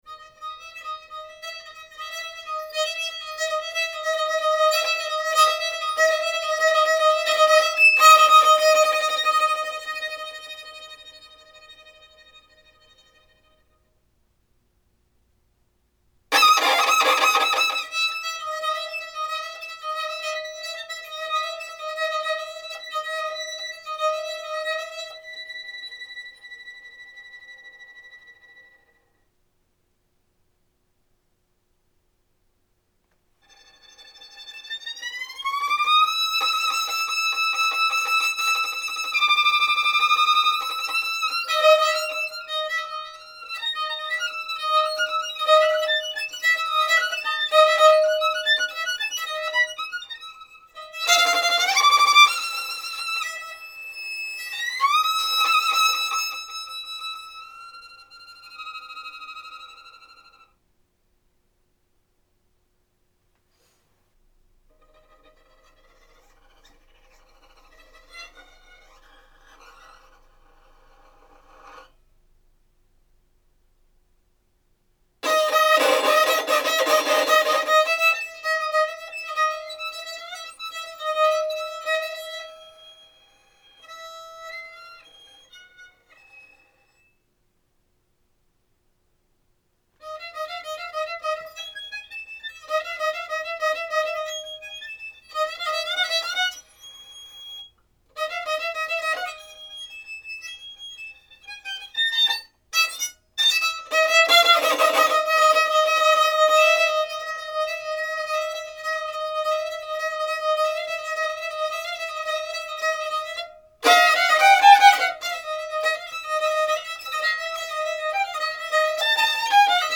Instrumentation: “simplified” violin